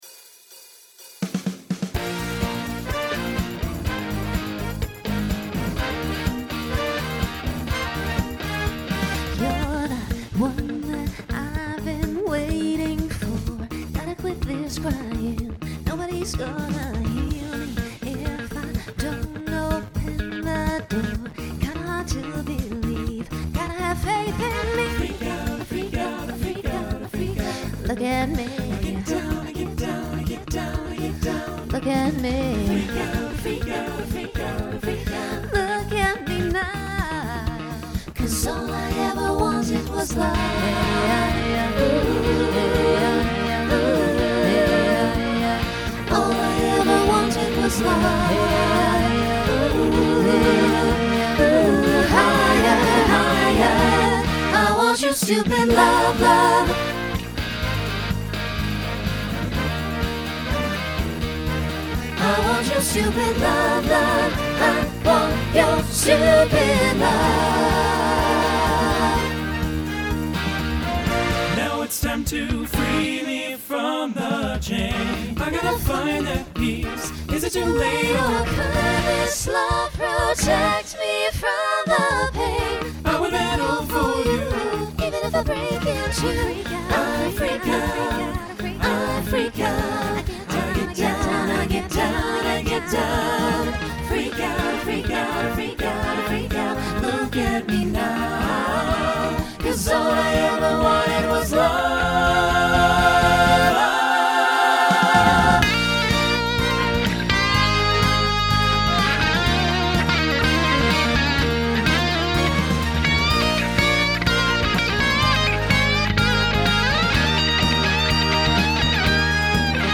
Pop/Dance
Voicing SATB